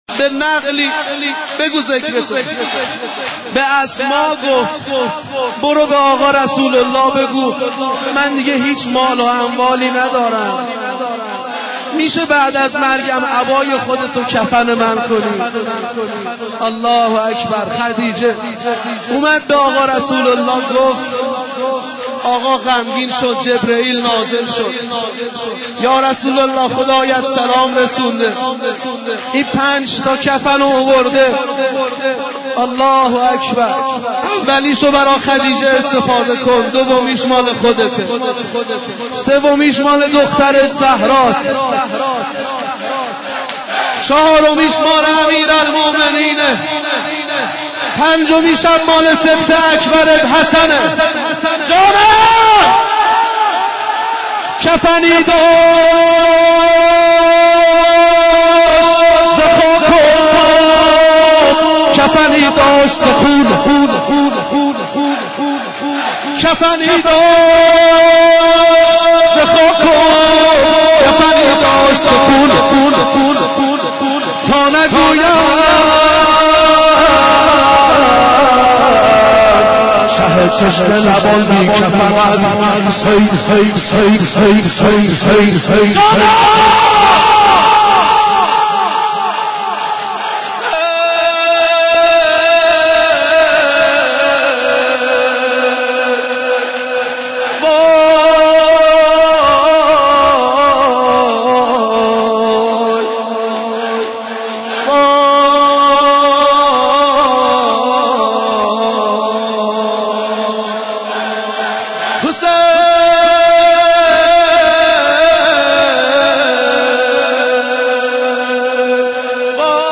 نوحه خوانی و سینه زنی